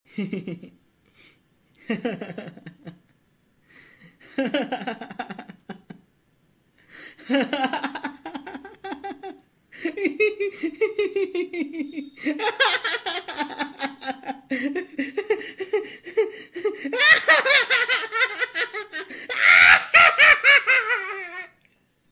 All EMOJI SOUNDs 🤣😂. sound effects free download
You Just Search Sound Effects And Download. tiktok funny sound hahaha Download Sound Effect Home